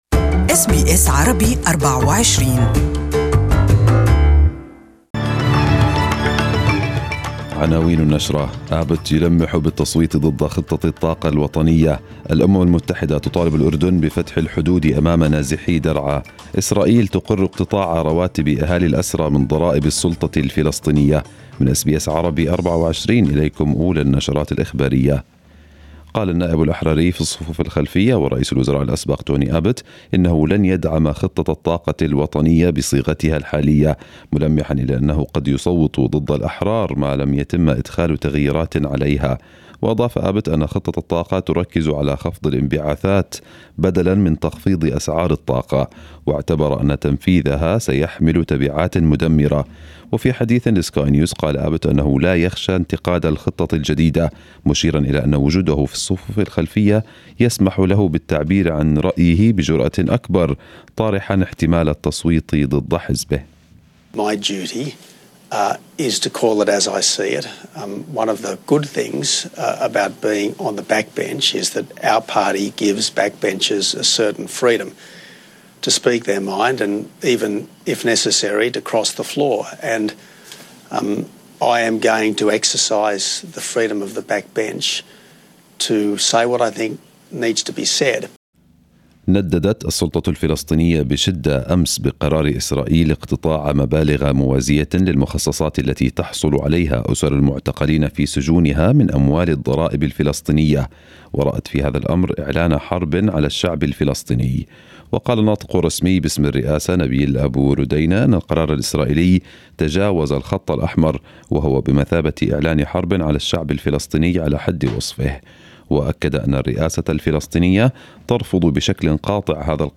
News Bulletin 04/07/2018